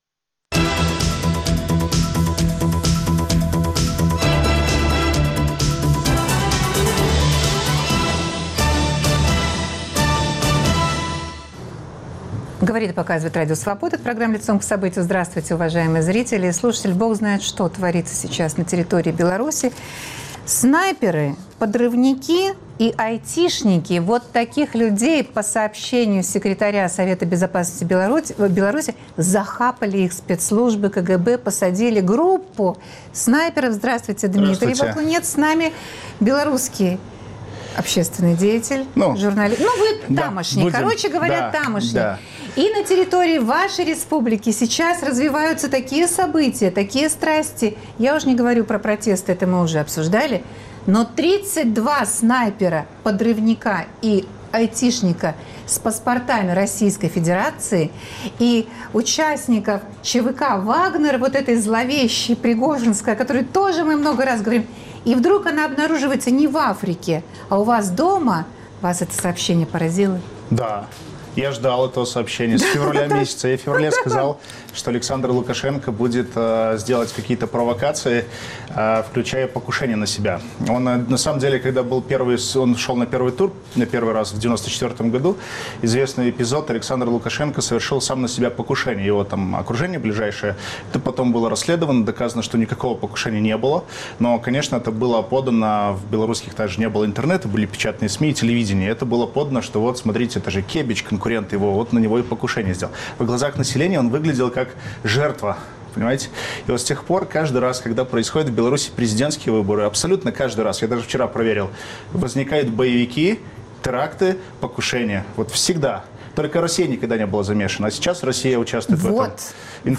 Разбираемся с экспертами.